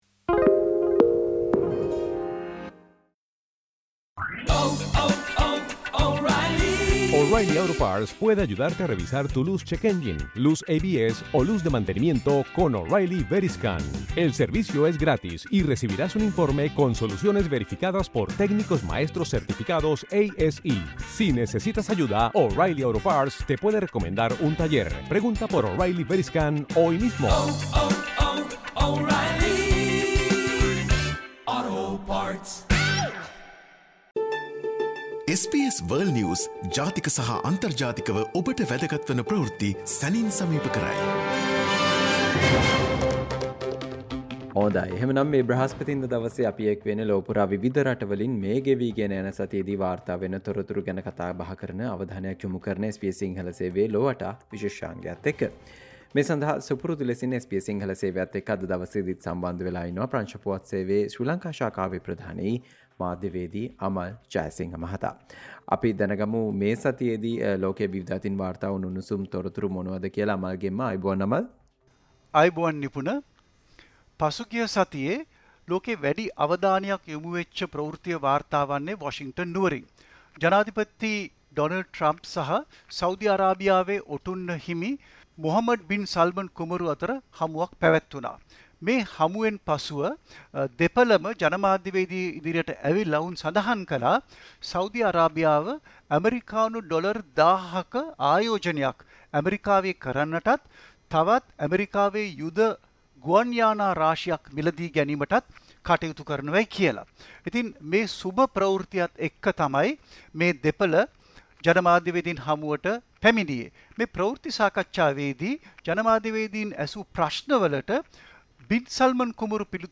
සතියේ විදෙස් පුවත් විග්‍රහය